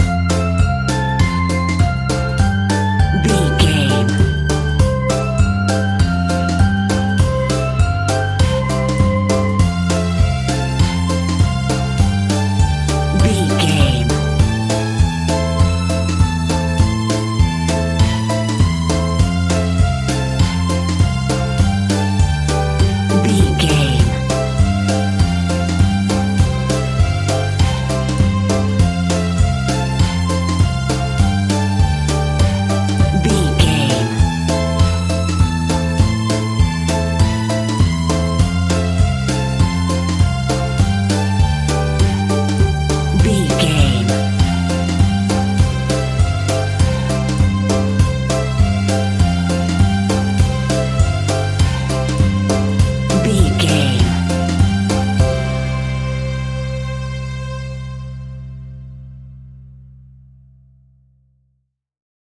Ionian/Major
childrens music
instrumentals
childlike
cute
happy
kids piano